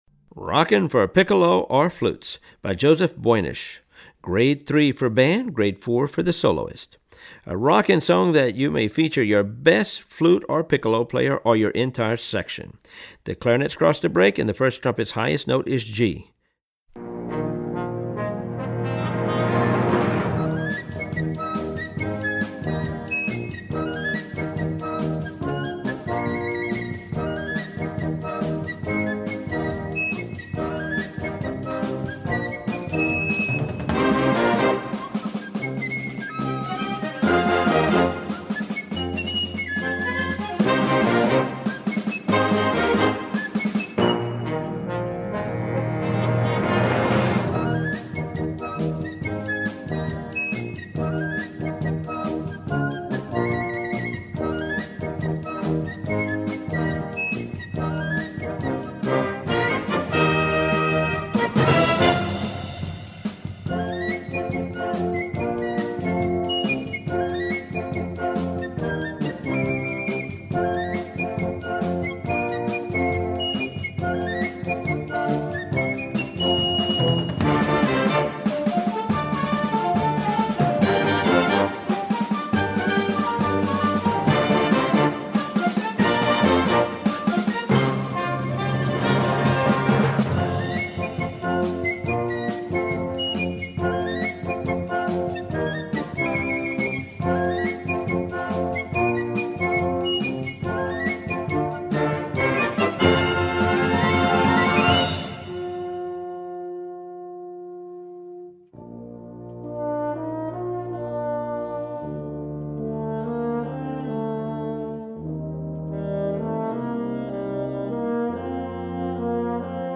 Voicing: Piccolo w/ Band